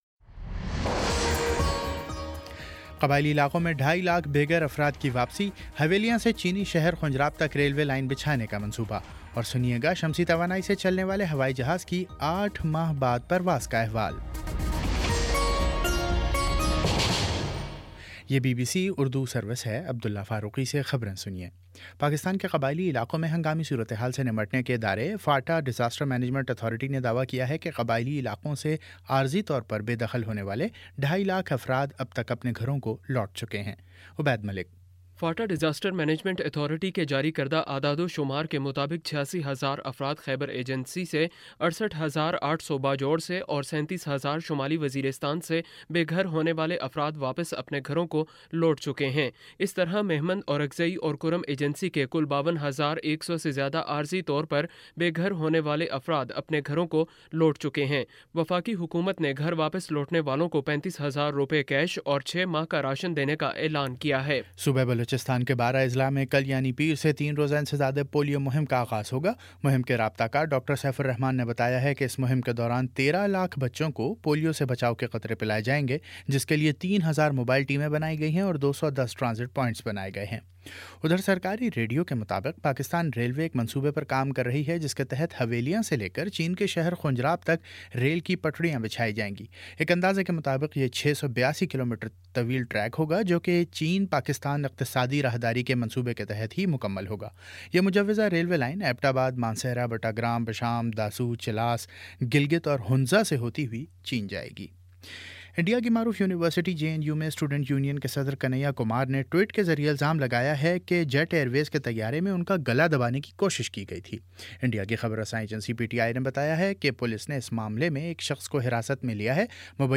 اپریل 24 : شام چھ بجے کا نیوز بُلیٹن